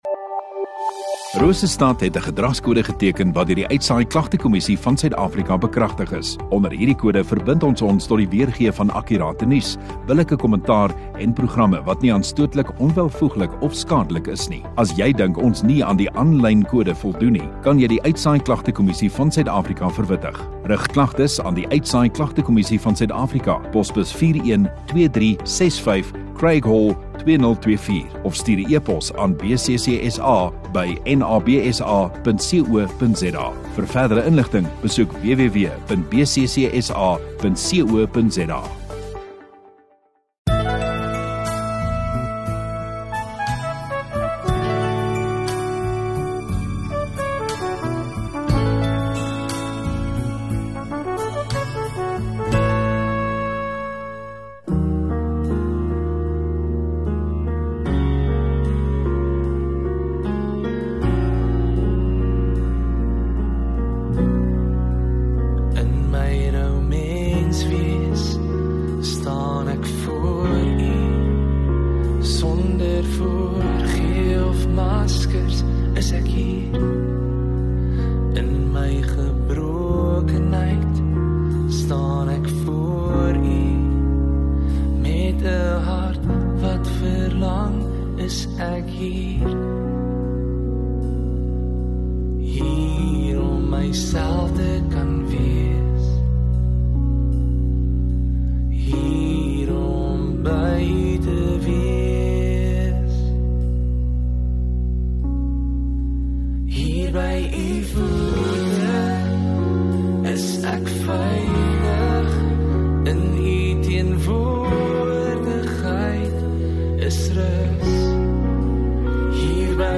1 Jun Sondagoggend Erediens